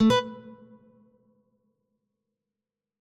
harmony2 - Dream Sounds: Harmony 2 sound theme for KDE Plasma
dialog-warning.ogg